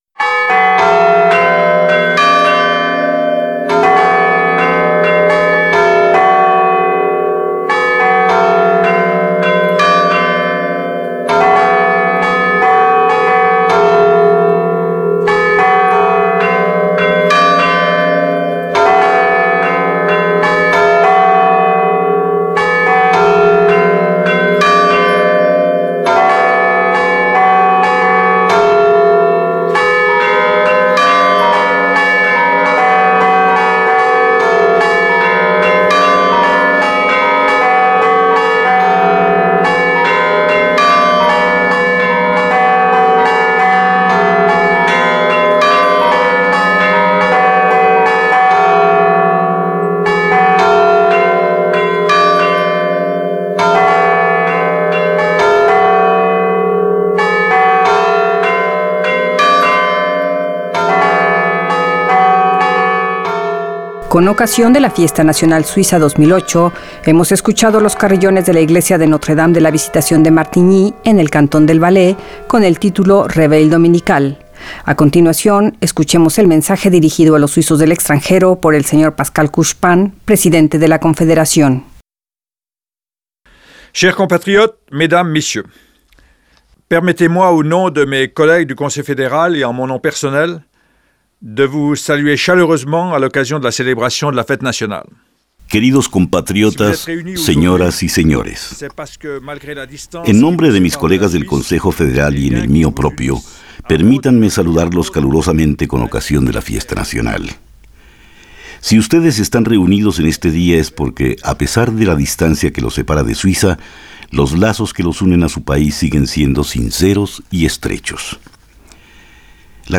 Discurso del presidente de la Confederación Pascal Couchepin a los suizos del exterior.